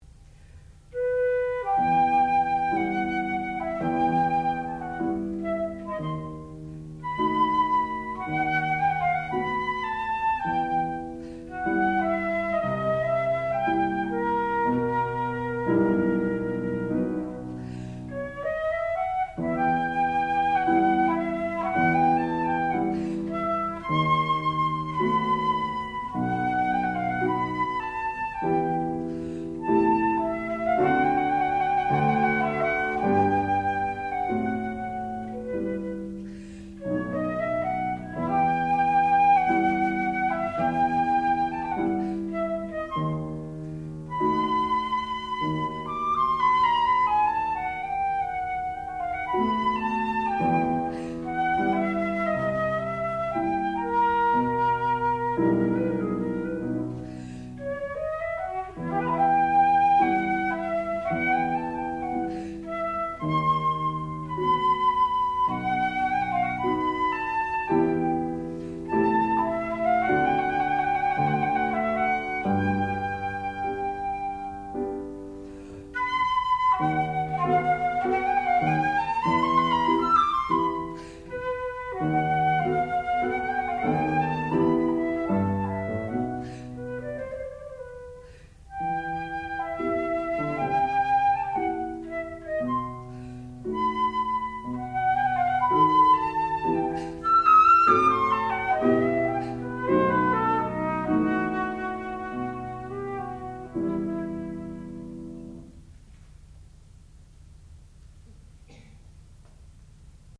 classical music
piano
Advanced Recital
flute